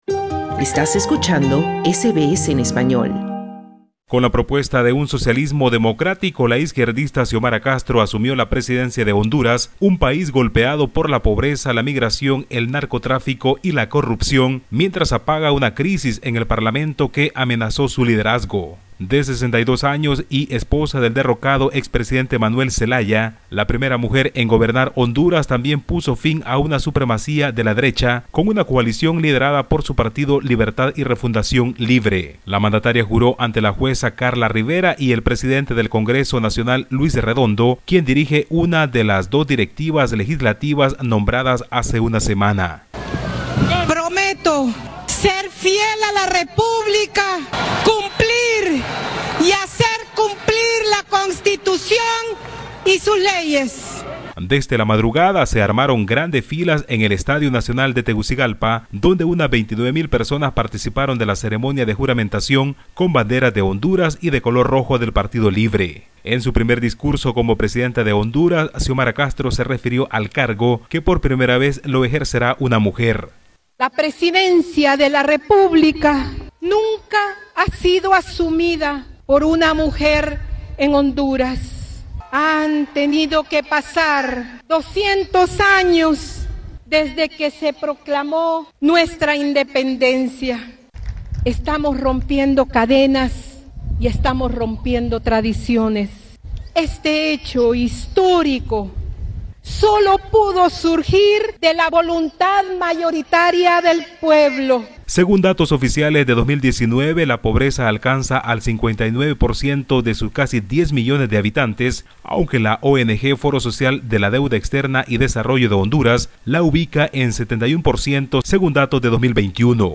Honduras es gobernada desde hoy por la izquierdista Xiomara Castro quien confirmó que recibe un país en quiebra por lo que anunció refundarlo en un estado socialista democrático. Escucha el informe del corresponsal de SBS Spanish en Latinoamércia